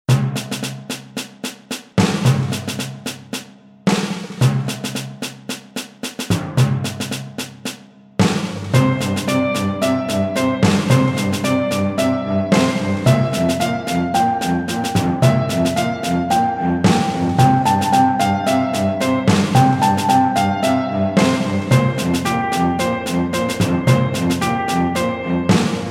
-  Mp3 Mp3 Instrumental Song Track